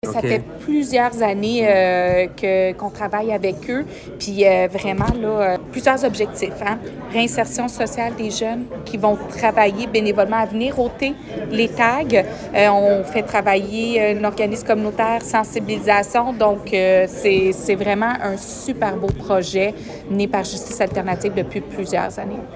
La mairesse de Granby, Julie Bourdon :